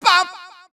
babumbumbum sounds
baBumBumBum_Farther3.wav